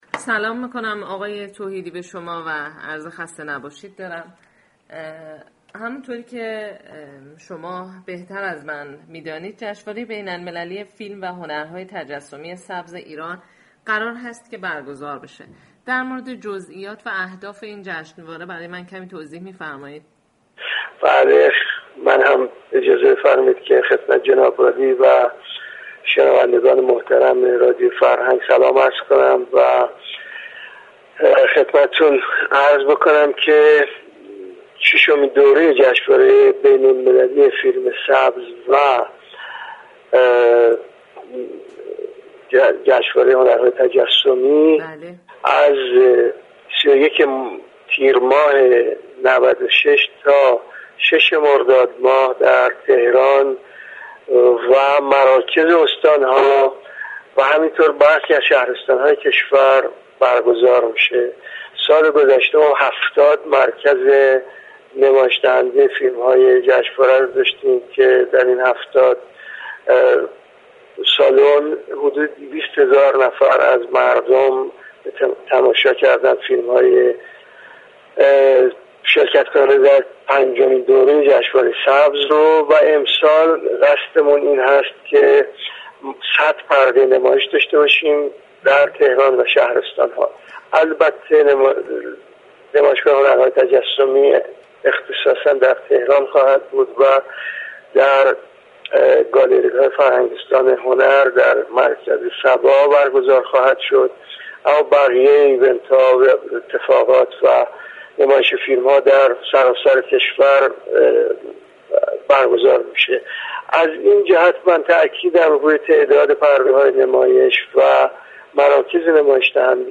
آقای فرهاد توحیدی در گفتگو ی اختصاصی با سایت رادیو فرهنگ درباره ی جز ییات و اهداف بر گزاری این جشنواره گفت : این جشنواره سال گذشته در 70 مركز نمایش فیلم برگزار شد كه در این 70 سالن حدود 20 هزار نفر از مردم به تماشای فیلم های شركت كننده در پنجمین جشنواره ی بین المللی فیلم و هنرهای تجسمی سبز نشستند .